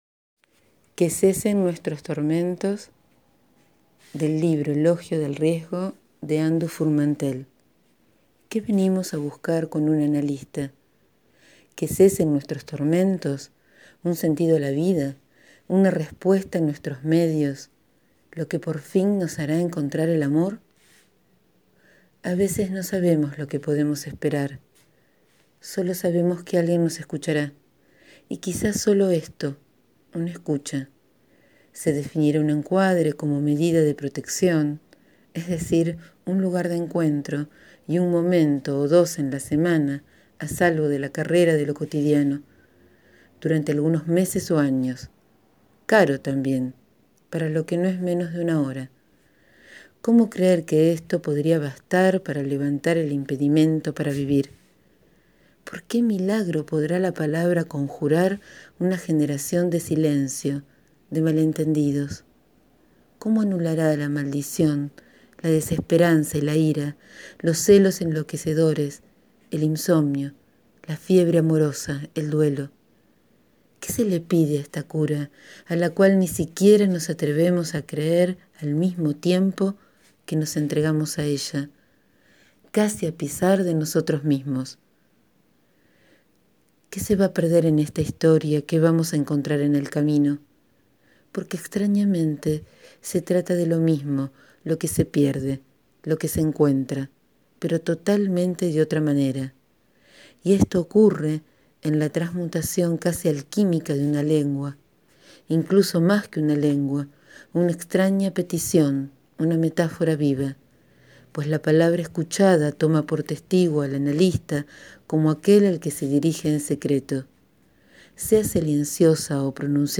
Hoy leo «Que cesen nuestros tormentos….» del libro «Elogio del riesgo» de Anne Dufourmantelle (1964-2017).